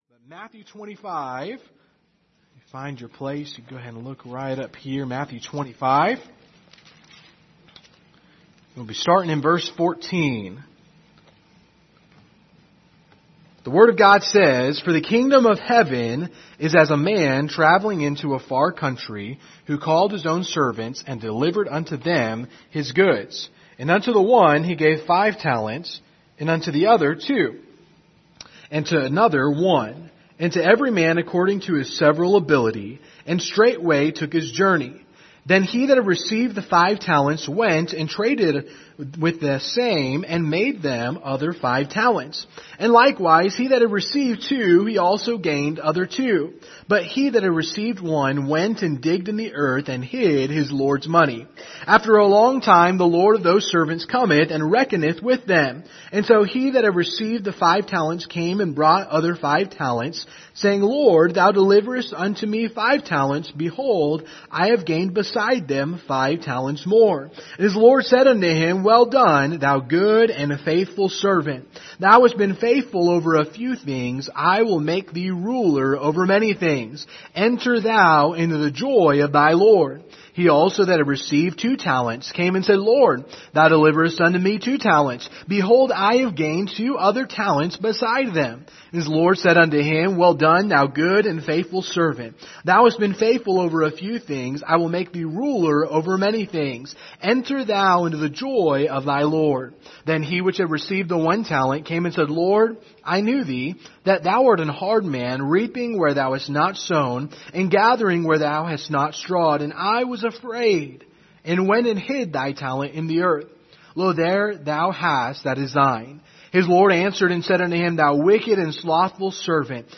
Passage: Matthew 25:14-30 Service Type: Sunday Evening